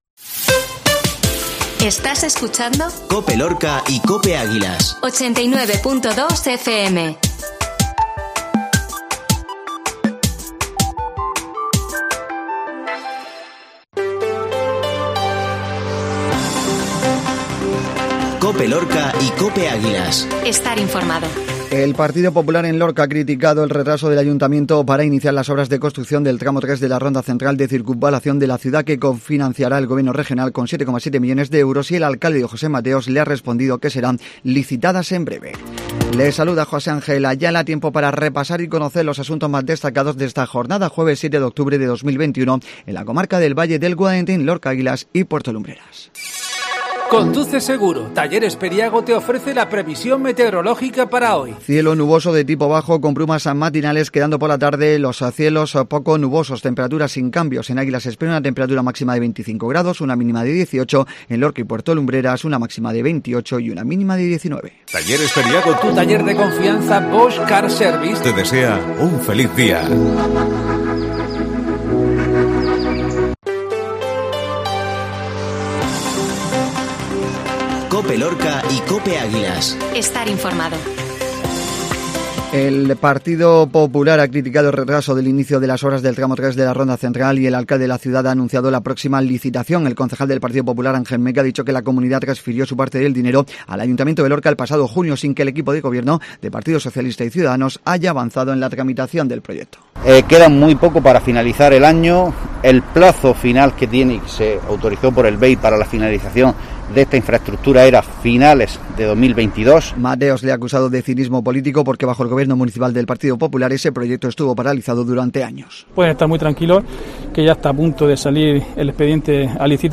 INFORMATIVO MATINAL JUEVES